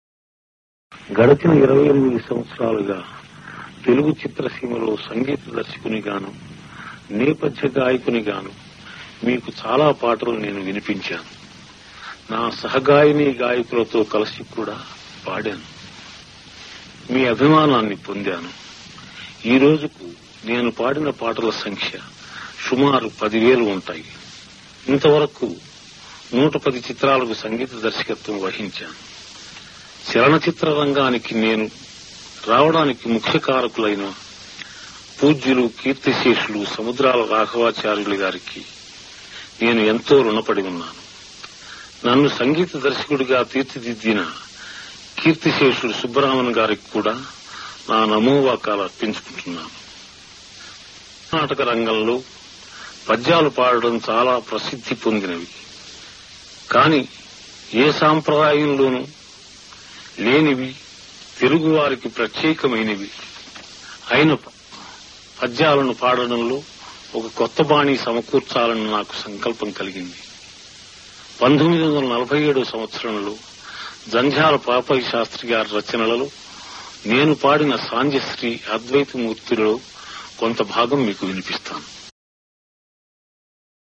The master's favorite songs, introduced and made real by his commentary, in his own voice. These are collected from two "Prathyeka Janaranjani" programs broadcast by AIR.
To save diskspace, these are saved as 64Bit Mono files; They are not suitable for CD burning without further audio processing.
Introduction to the first program